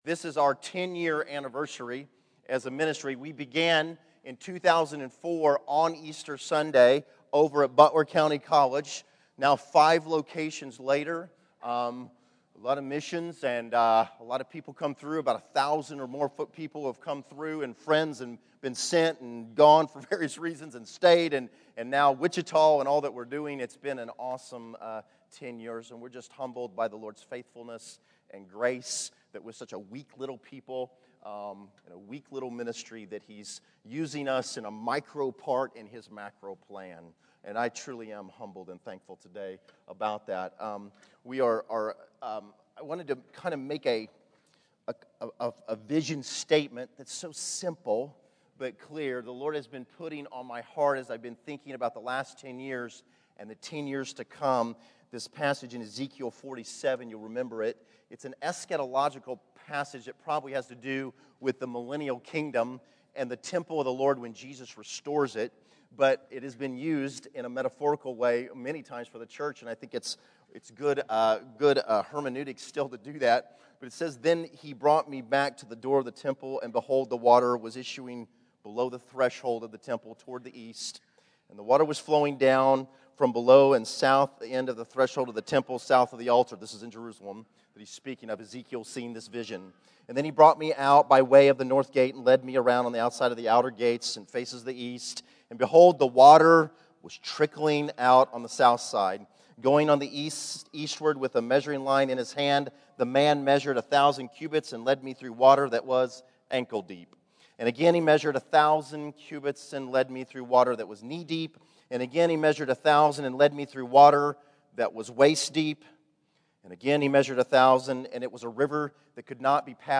Category: Testimonies      |      Location: El Dorado